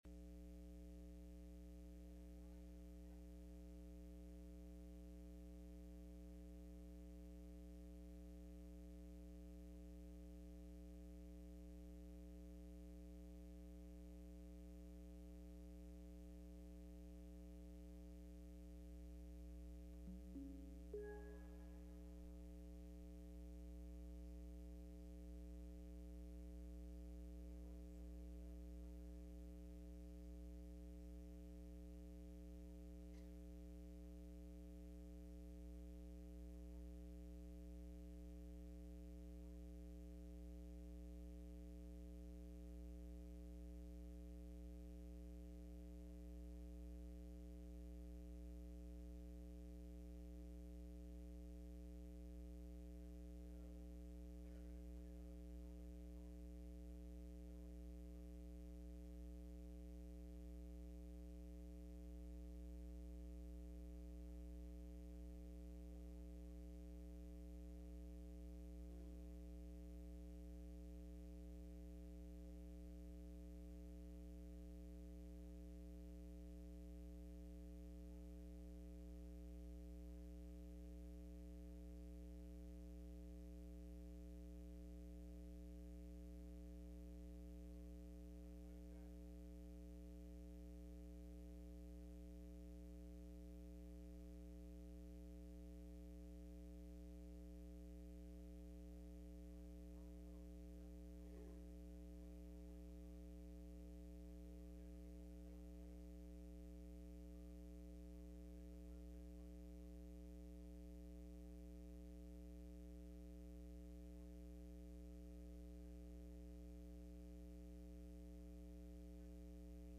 Wednesday Night Service
Service Type: Midweek Meeting